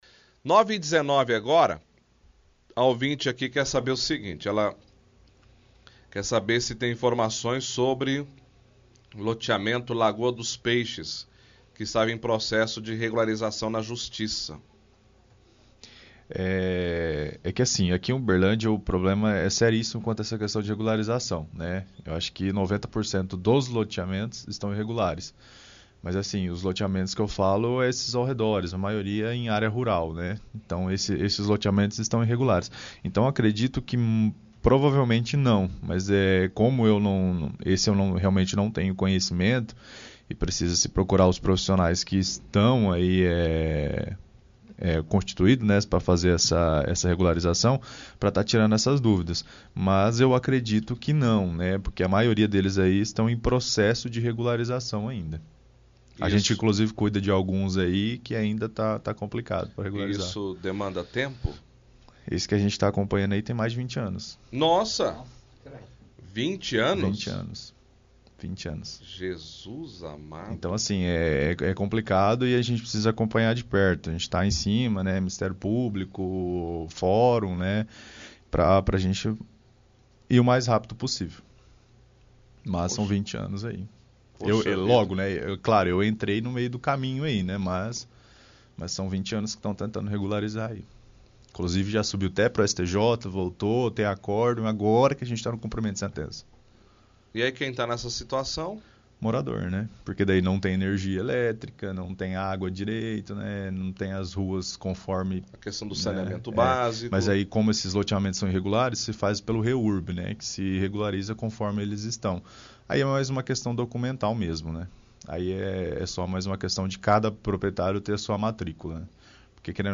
– Ouvinte questiona se o advogado, convidado do programa, tem informações sobre regularização do loteamento Lagoa dos Peixes.